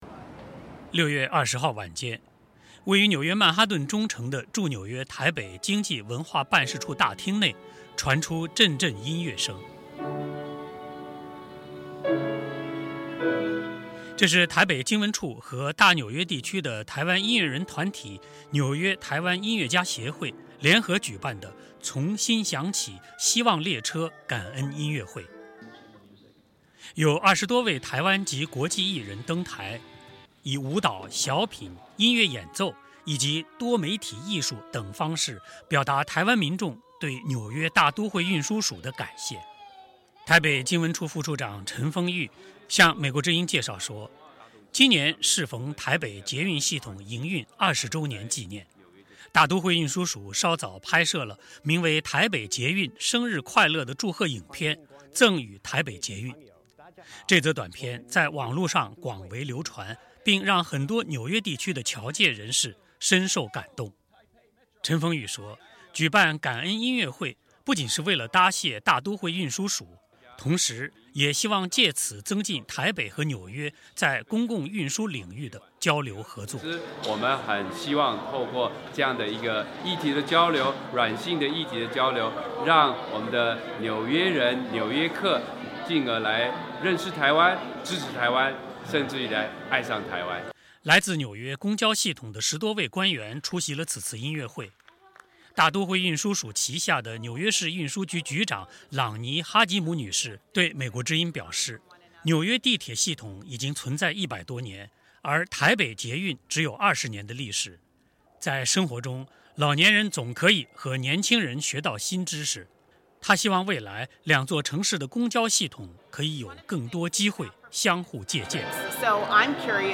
6月20日晚间，位于曼哈顿中城的驻纽约台北经济文化办事处大厅内传出阵阵音乐声。